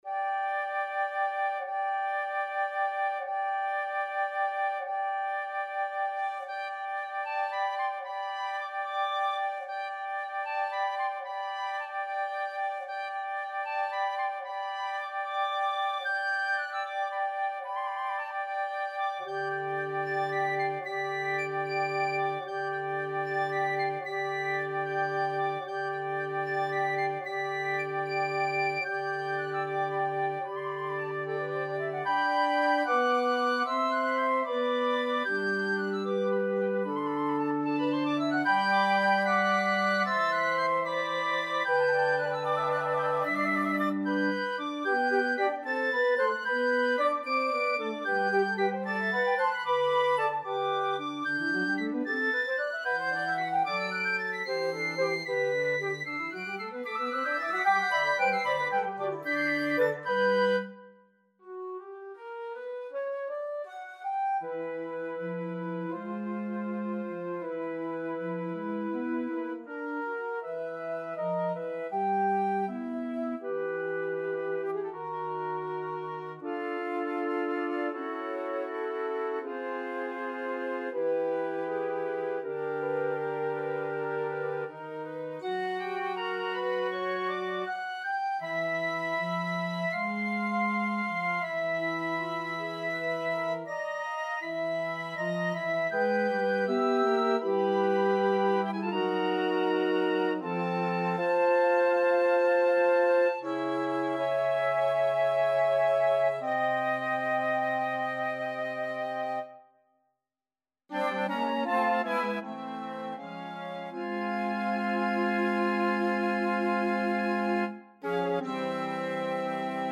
a 5 piece movement